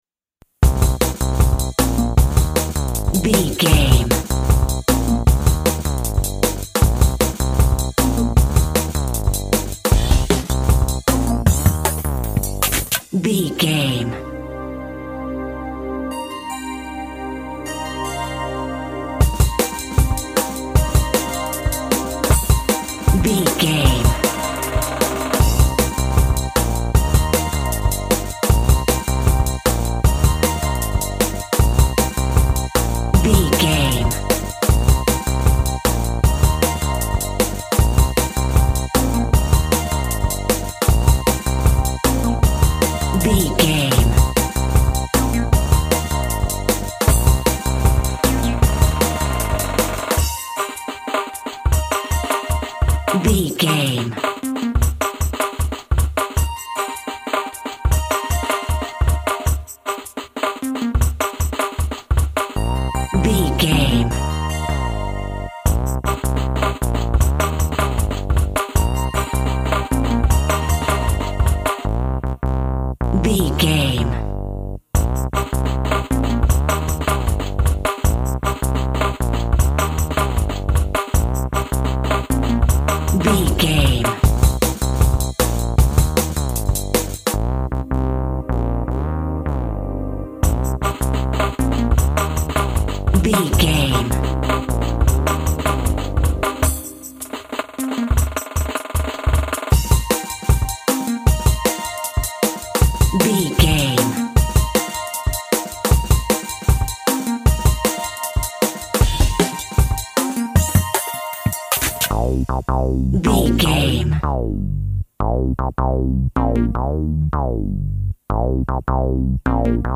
Big Drum & Bass Music.
Aeolian/Minor
Fast
frantic
driving
energetic
hypnotic
industrial
drum machine
synthesiser
synth lead
synth bass